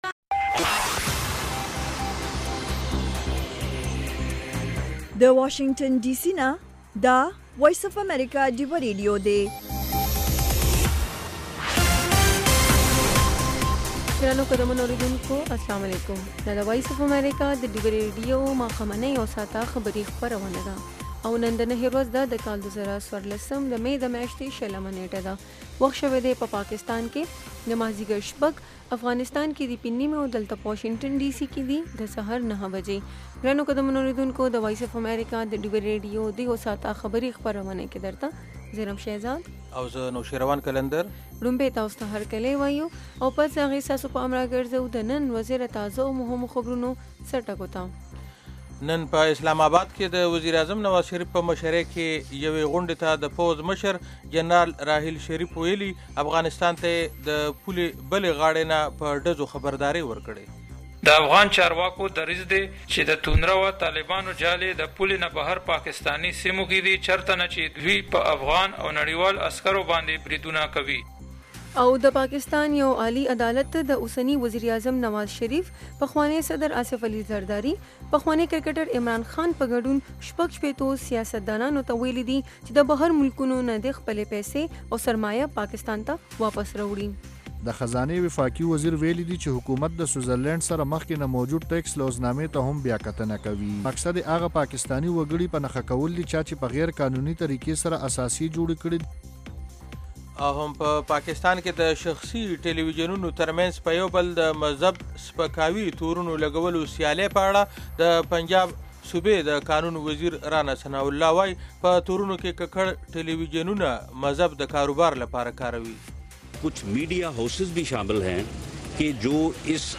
د وی او اې ډيوه راډيو ماښامنۍ خبرونه چالان کړئ اؤ د ورځې د مهمو تازه خبرونو سرليکونه واورئ. په دغه خبرونو کې د نړيوالو، سيمه ايزو اؤمقامى خبرونو Deewa Radio هغه مهم اړخونه چې سيمې اؤ پښتنې ټولنې پورې اړه لري شامل دي.